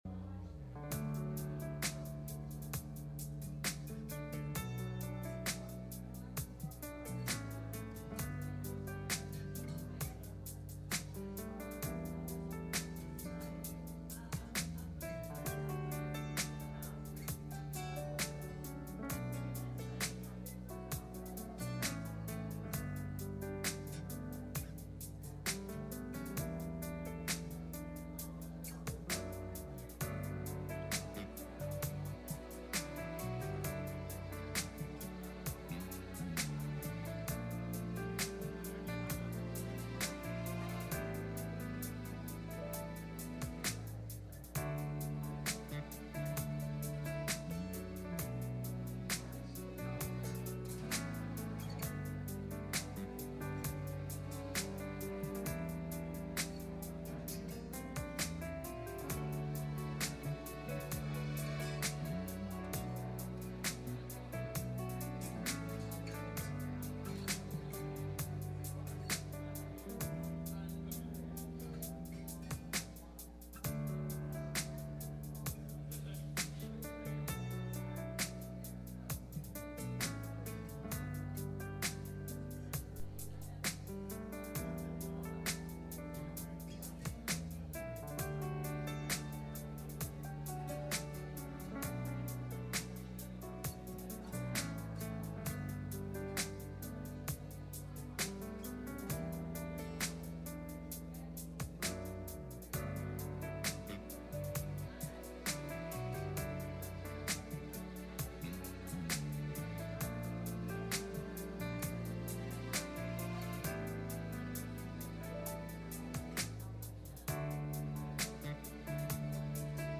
Ezekiel 37:1-3 Service Type: Sunday Morning « The Unashamed Father